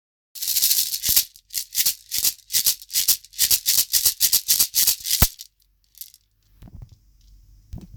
種シェケレ(シェイカー) M
ひょうたんに種をつけたシェケレです。ビーズタイプより音がきつくなく素朴で抜けのよい音色が特徴。
素材： ひょうたん 実 木綿糸